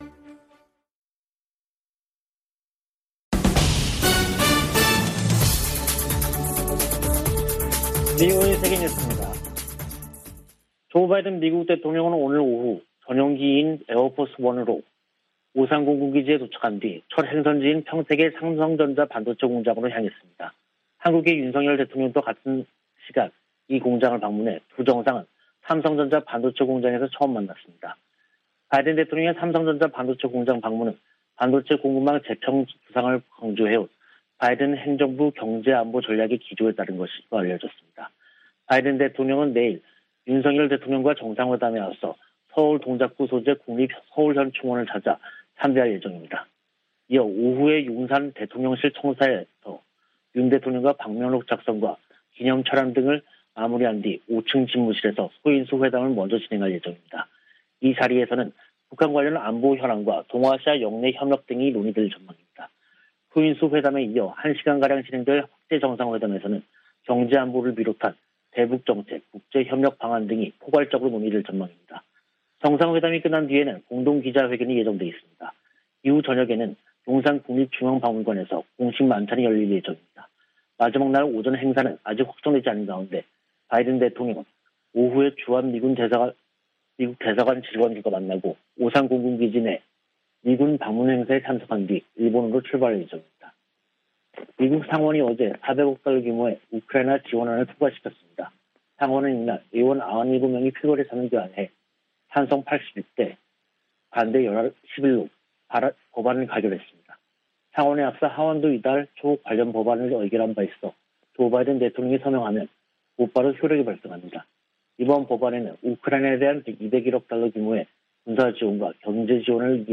VOA 한국어 간판 뉴스 프로그램 '뉴스 투데이', 2022년 5월 20일 2부 방송입니다. 조 바이든 미국 대통령이 방한 첫 일정으로 평택 삼성전자 반도체 공장을 방문해 미한 간 기술동맹을 강조했습니다. 경제안보 현안과 대응전략을 논의하는 백악관과 한국 대통령실 간 대화채널을 구축하기로 했습니다. 미 국방부는 북한의 도발에 대응해 민첩한 대비태세를 취하고 있다고 밝혔습니다.